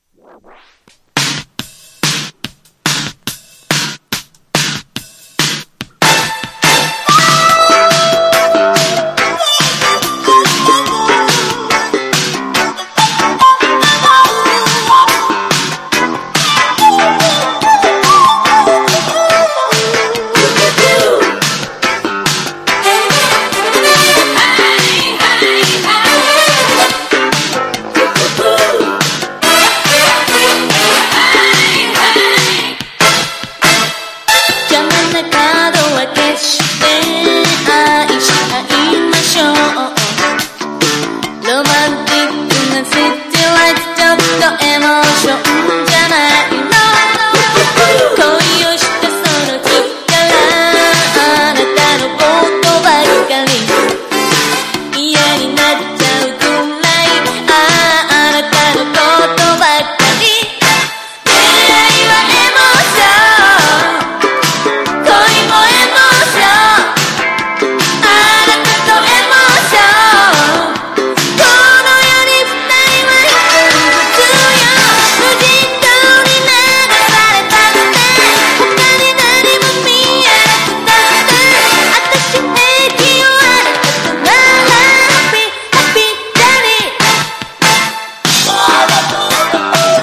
# POP# CITY POP / AOR# 和モノ